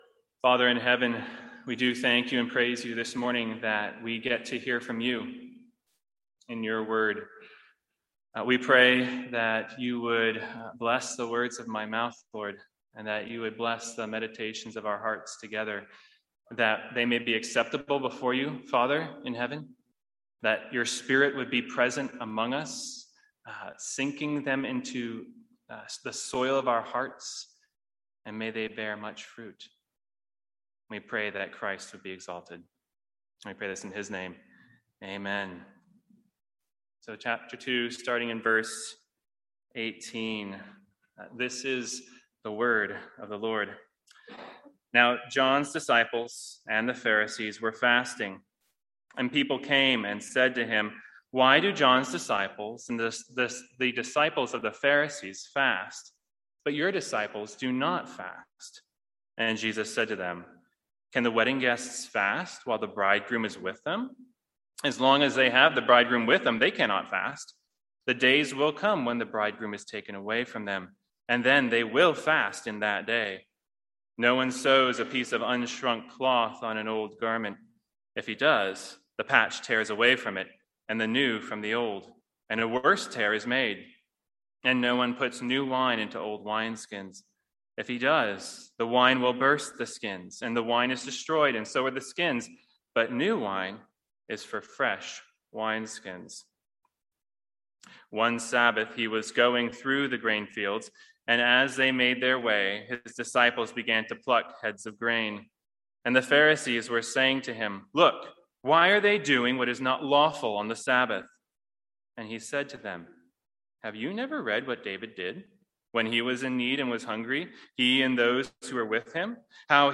Sermons | St Andrews Free Church
From our morning series in the Gospel of Mark.